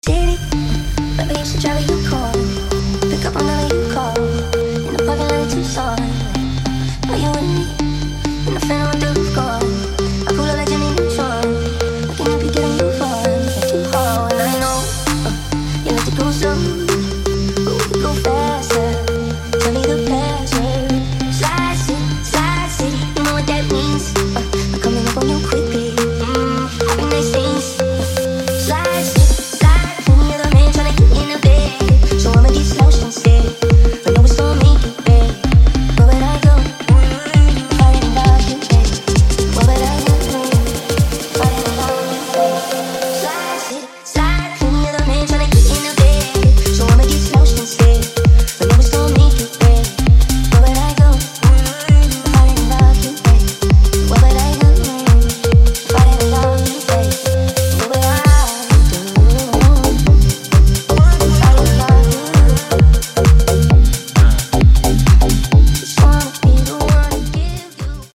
Genre: DANCE Version: Clean BPM: 132 Time